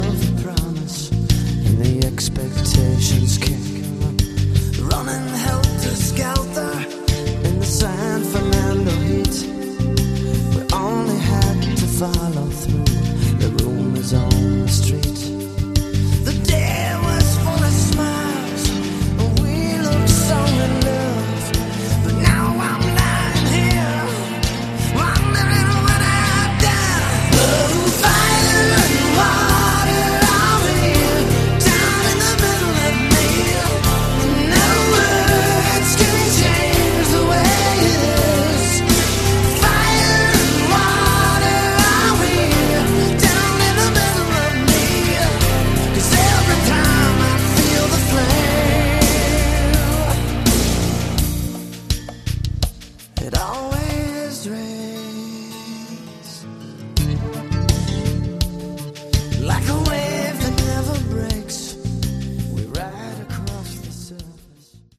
Category: AOR
vocals, keyboards, programming
guitars